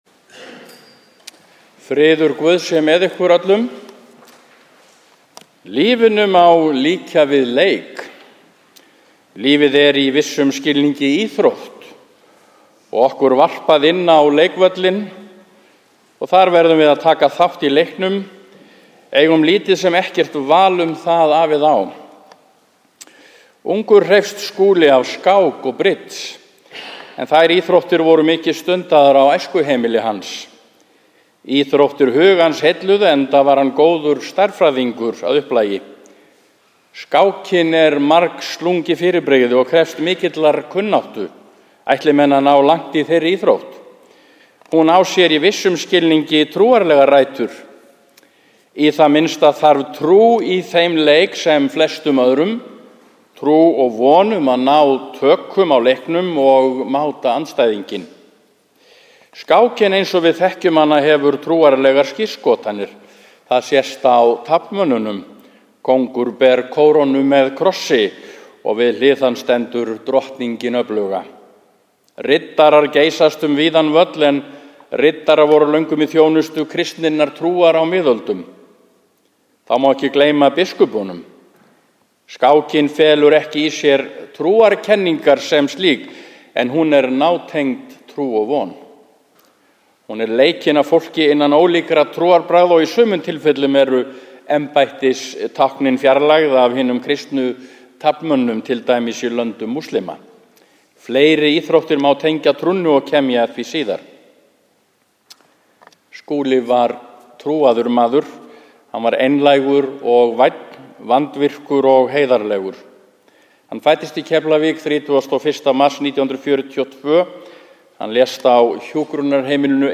Minningarorð